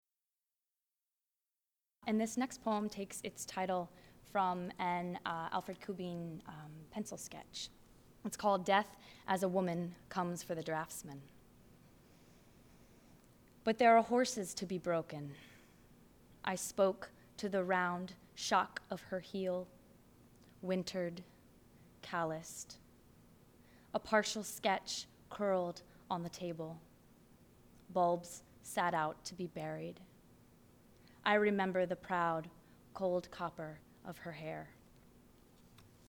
Death as a Woman Comes for the Draftsman (live)
Death-Draftsman-live.mp3